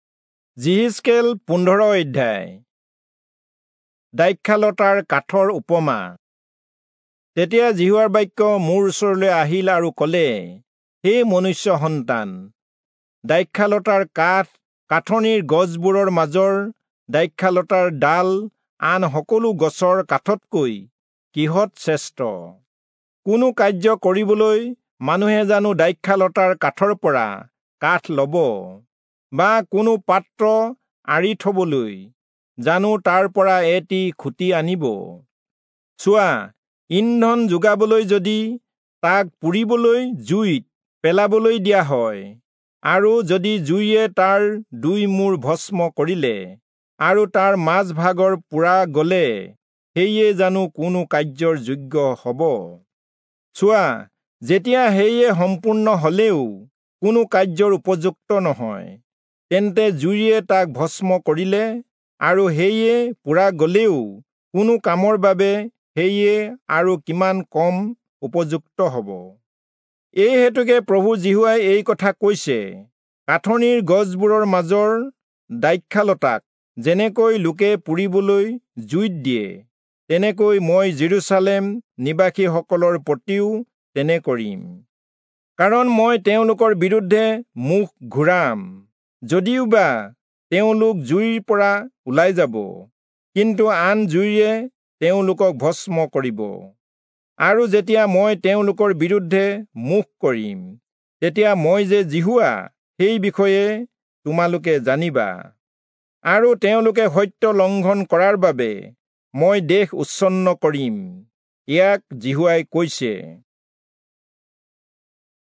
Assamese Audio Bible - Ezekiel 13 in Irvmr bible version